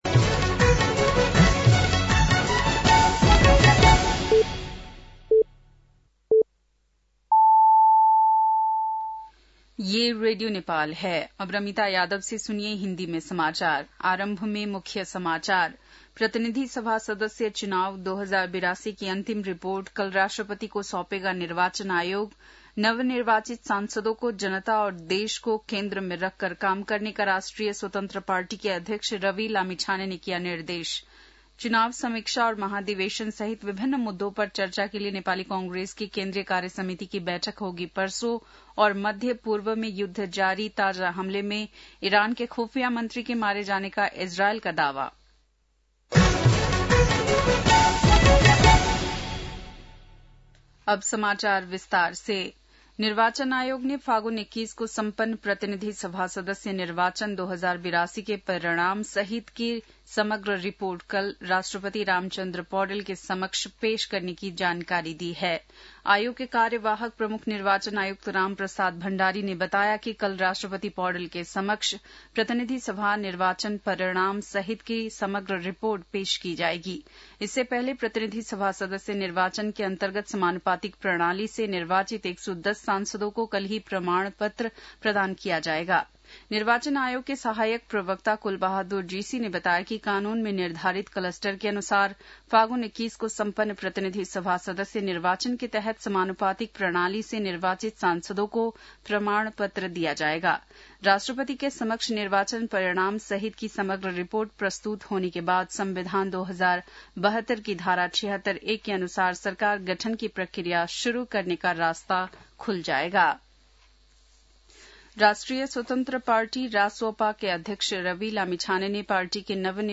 बेलुकी १० बजेको हिन्दी समाचार : ४ चैत , २०८२